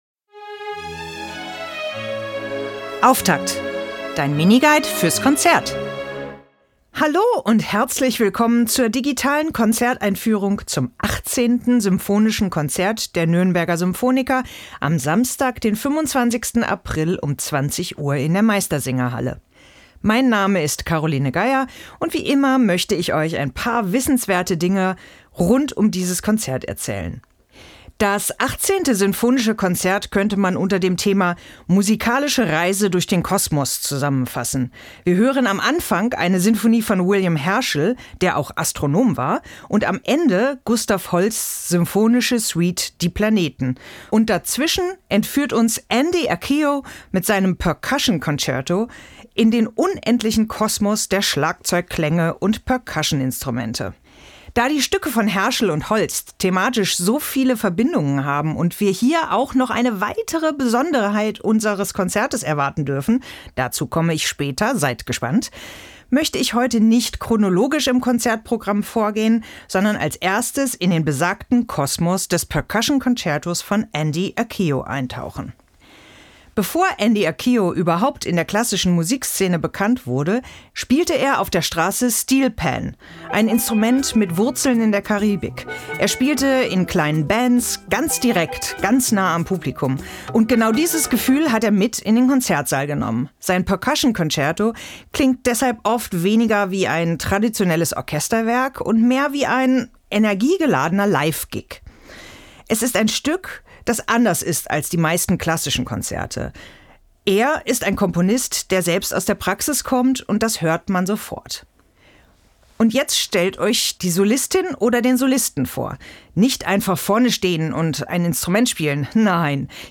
Podcast-Moderation